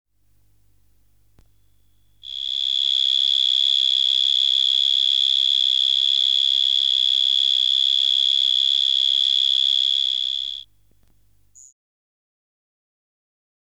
4. 1. 울음소리
''Gryllotalpa gryllotalpa''의 노래
수컷 땅강아지는 항상 땅 속에서 스트리둘레이션을 통해 노래한다.[15] ''Gryllotalpa gryllotalpa''의 노래는 3.5kHz의 거의 순수한 음을 기반으로 하며, 굴 주변 20cm까지 땅을 진동시킬 정도로 크다.
결과적인 노래는 순수한 음을 66-Hz 파동으로 변조하여 규칙적인 칩 소리를 형성하는 것과 유사하다.